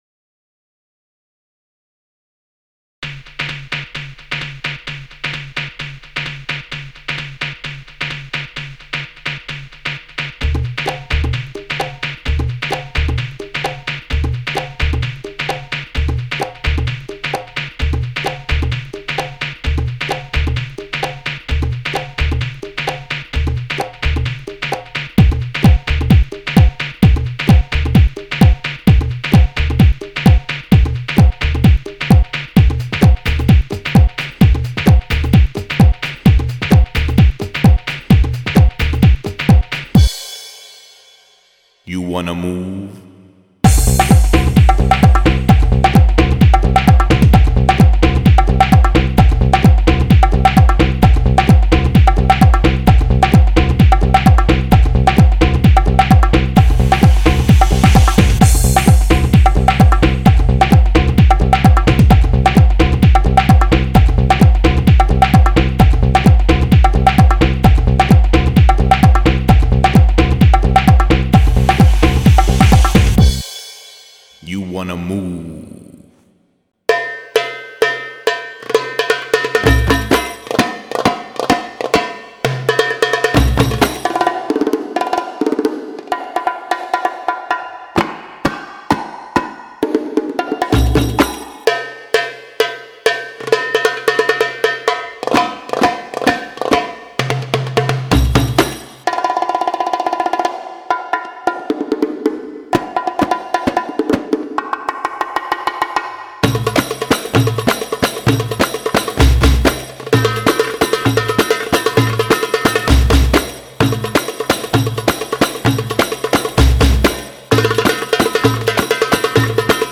• دانلود آهنگ بی کلام ضرب دار برای ساخت کلیپ